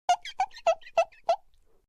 squeak.mp3